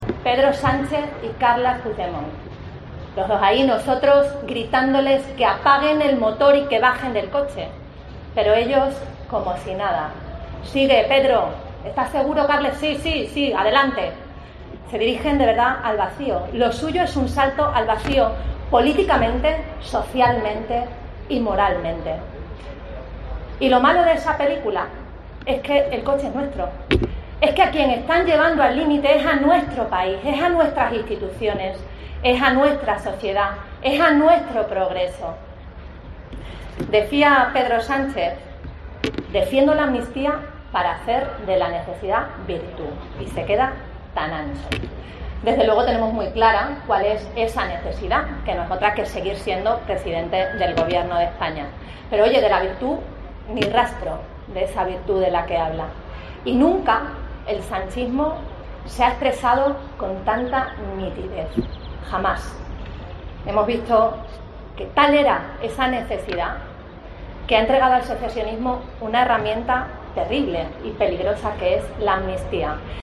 Así se ha pronunciado Guardiola este sábado en Cáceres, en un acto enmarcado en la 'Ruta por la Igualdad', organizado por el PP en todo el país, donde ha comparado a Sánchez y Puigdemont con Thelma y Louise, protagonistas de la película homónima dirigida por Ridley Scott.